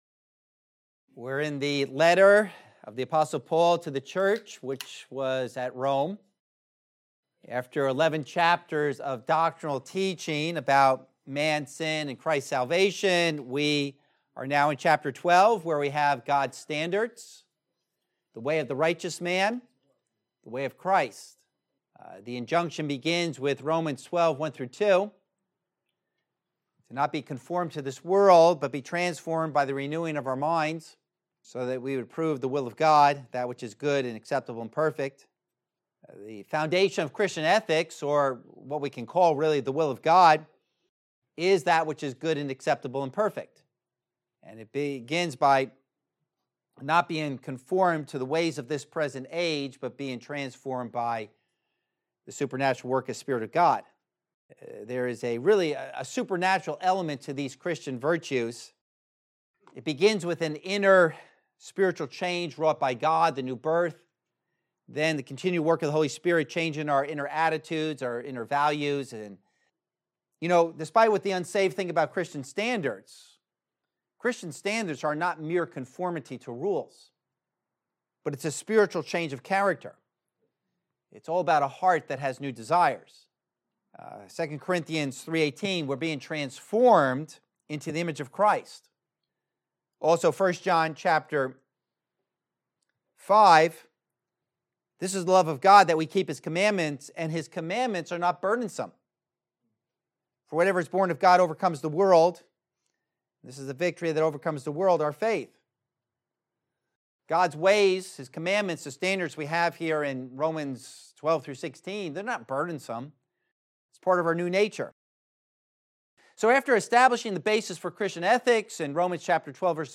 Romans Series #40 12:17-21 - The Law of Non-retaliation | Message